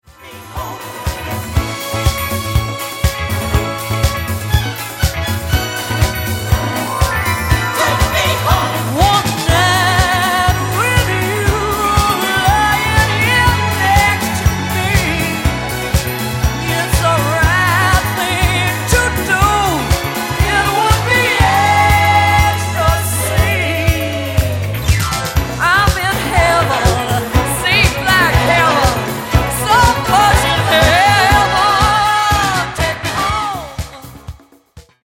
Genere:   Disco| Funky | Soul |